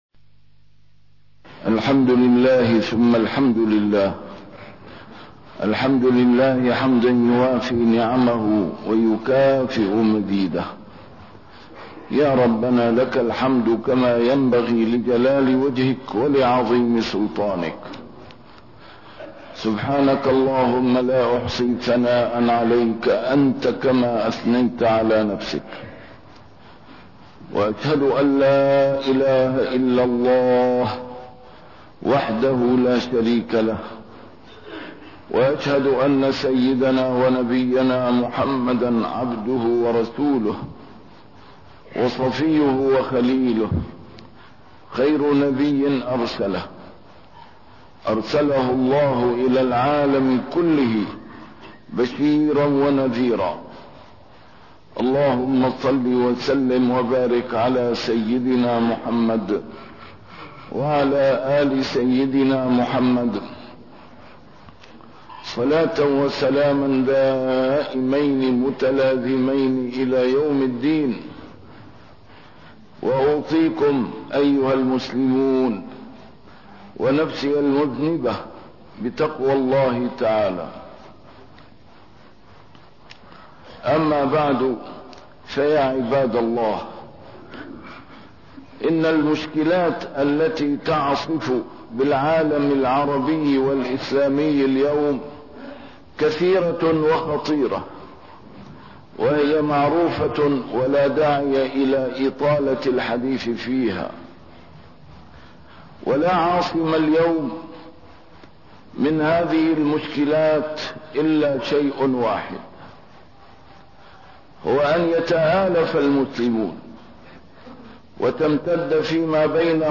A MARTYR SCHOLAR: IMAM MUHAMMAD SAEED RAMADAN AL-BOUTI - الخطب - محبة الرسول صلى الله عليه وسلم دافع لاتباعه